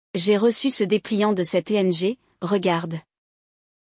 Hoje, vamos escutar o diálogo de dois amigos conversando sobre uma organização não governamental.
LE DIALOGUE